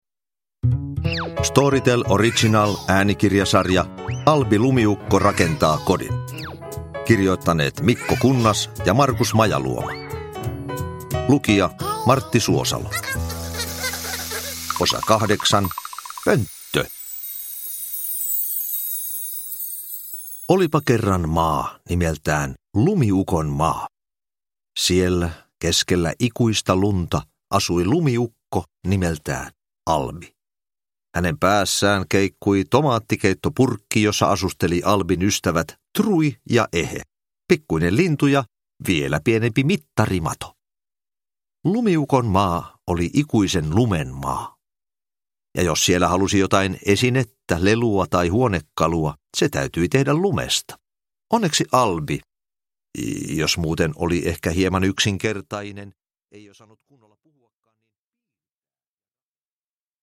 Albi rakentaa kodin: Pönttö – Ljudbok – Laddas ner
Uppläsare: Martti Suosalo